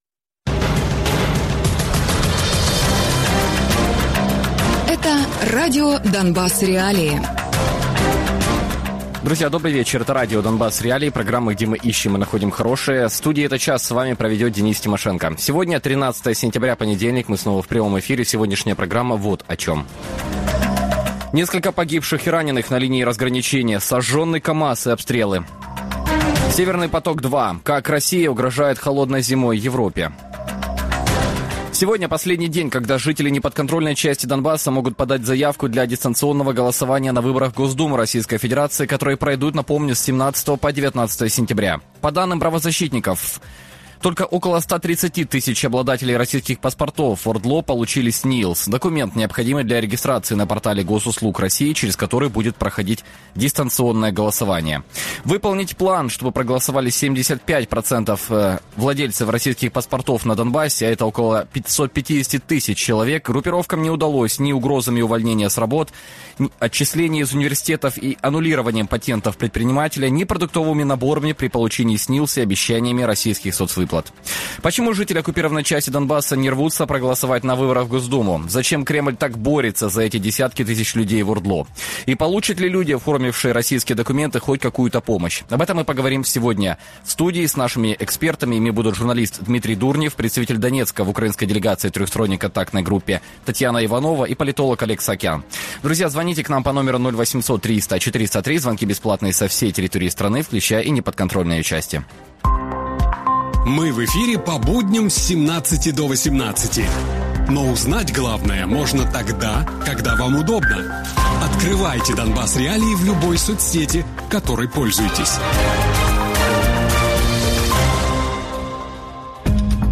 Гості програми радіо Донбас.Реалії